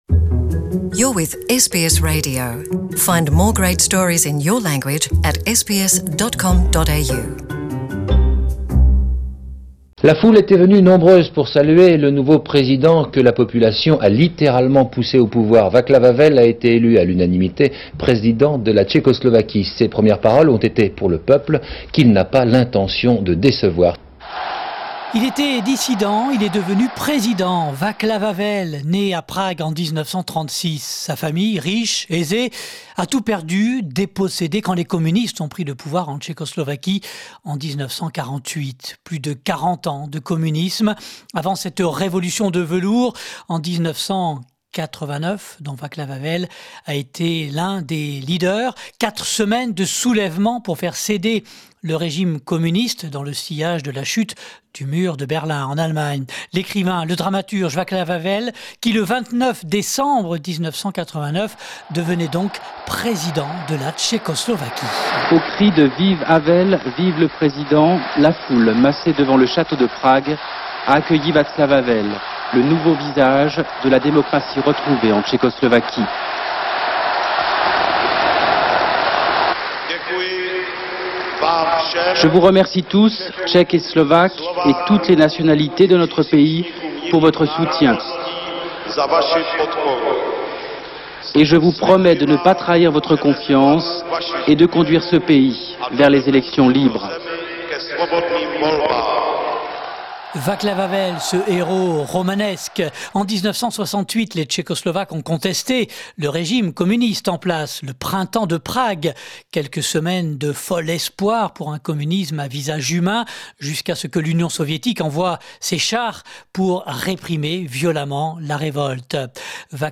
Le 29 décembre 1989, Vaclav Havel, l'écrivain dramaturge, était désigné président de la Tchécoslovaquie, symbole de liberté et de résistance après des années de communisme. Rétrospective réalisée grâce aux archives sonores de l'Institut National de l'Audiovisuel.